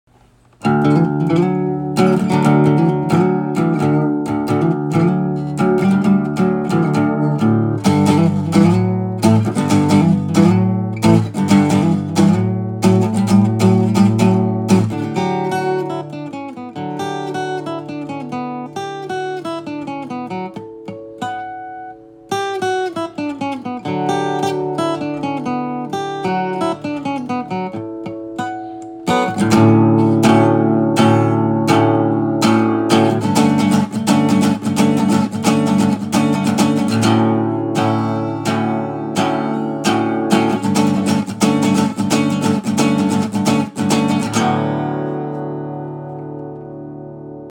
Messin around w some harmonics